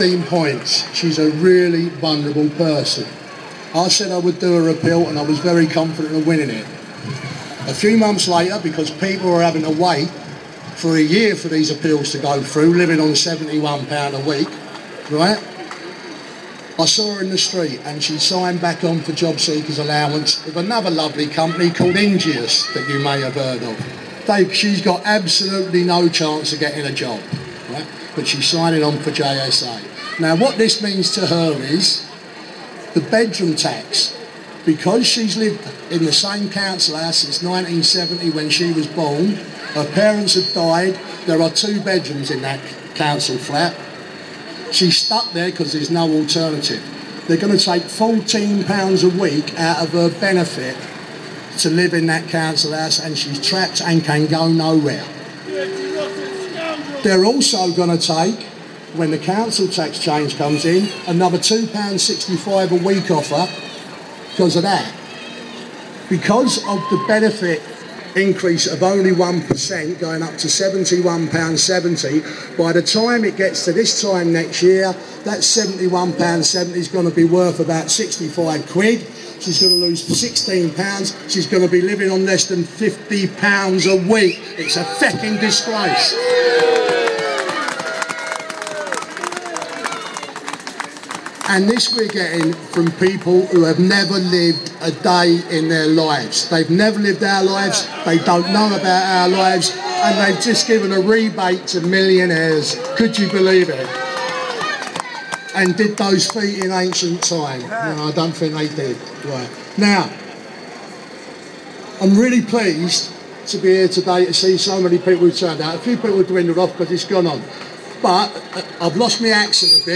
Bedroomtax Speech